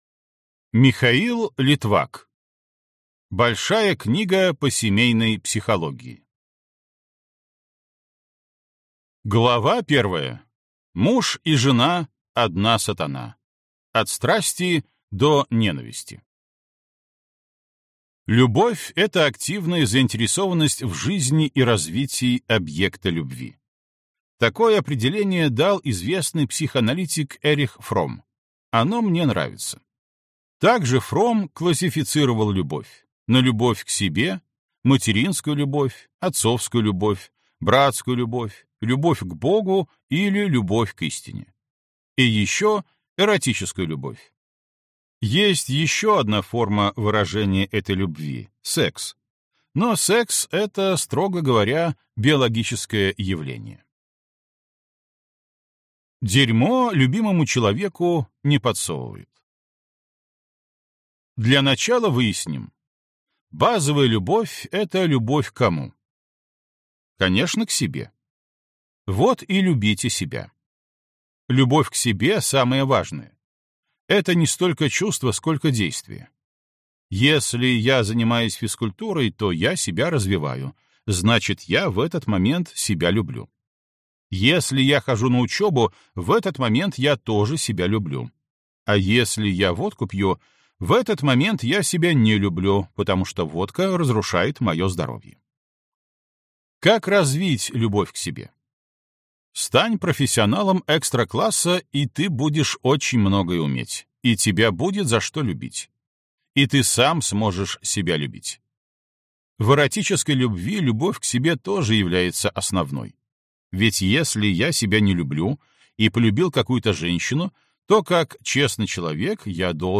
Аудиокнига Большая книга по семейной психологии | Библиотека аудиокниг
Прослушать и бесплатно скачать фрагмент аудиокниги